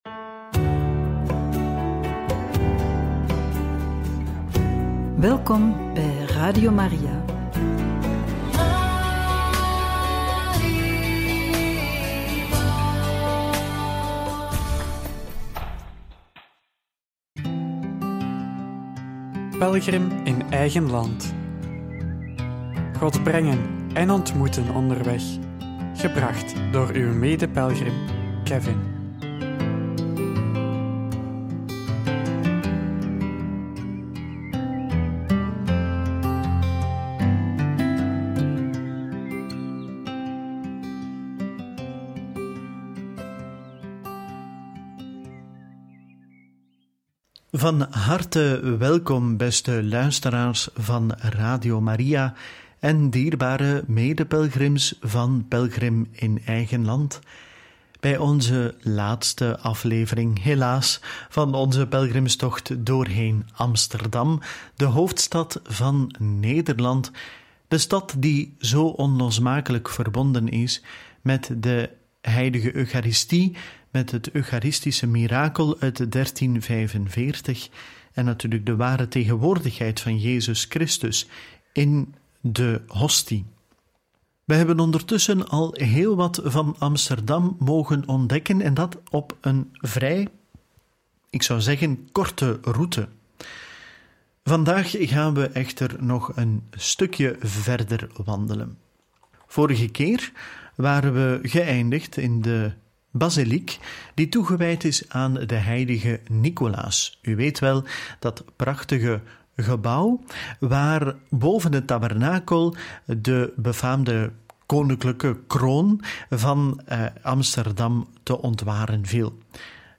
Ons Lieve Heer op Solder – Begijnhof – In gesprek met een Sacramentijn – Radio Maria